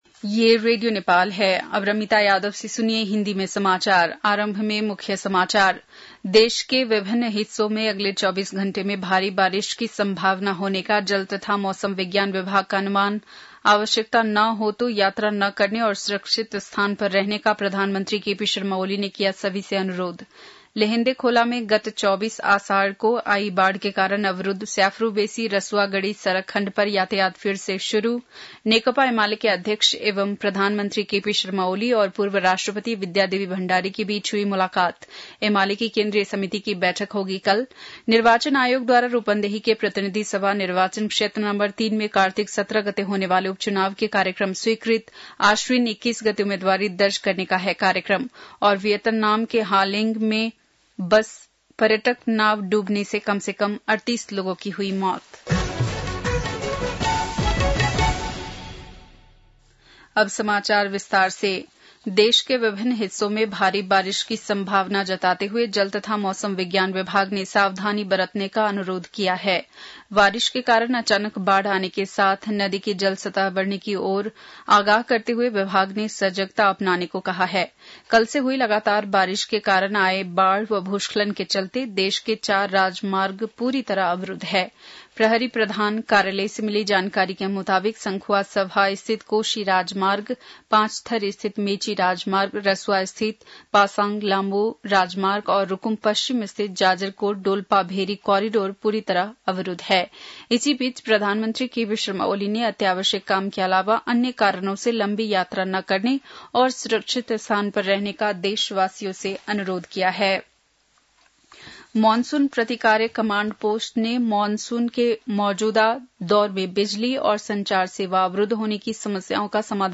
बेलुकी १० बजेको हिन्दी समाचार : ४ साउन , २०८२
10-pm-hindi-news-4-4.mp3